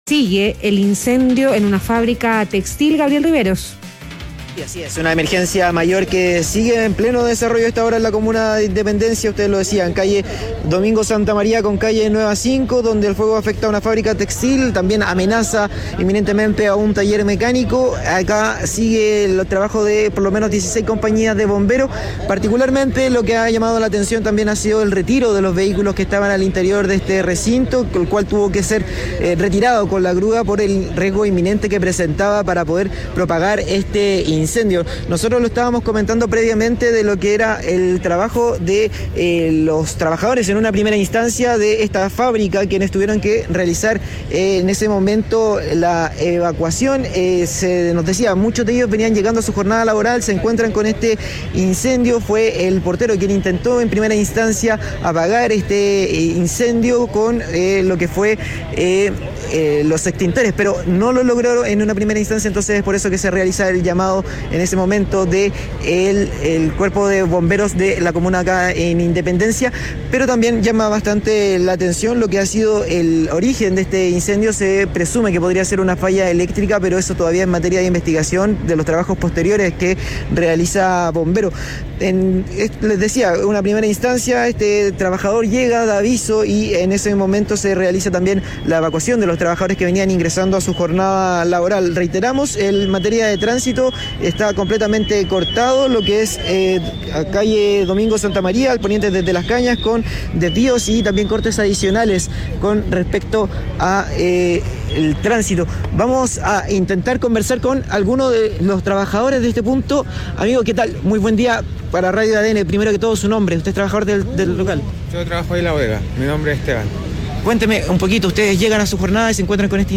En conversación con Radio ADN, uno de los trabajadores de la fábrica textil afectada se refirió a esta emergencia.